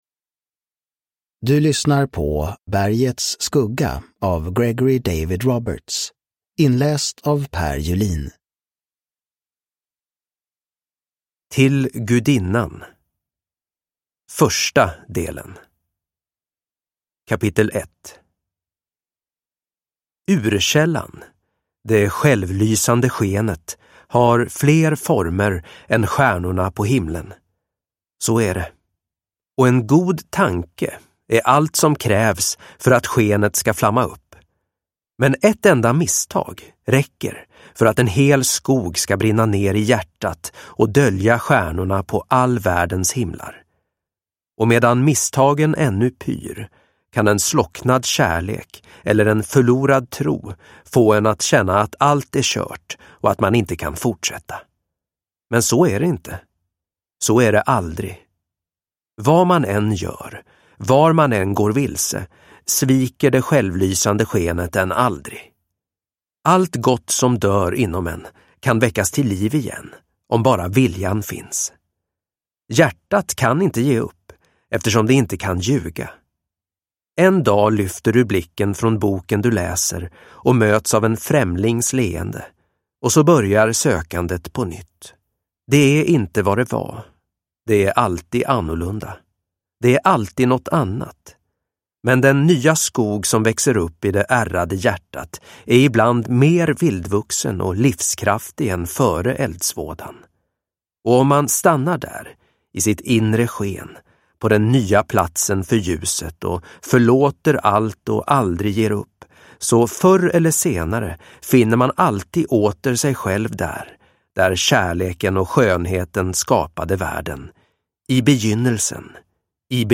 Bergets skugga – Ljudbok – Laddas ner